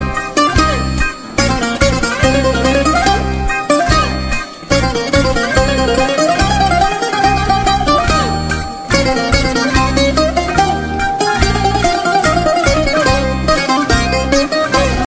Klingelton Griechische Musik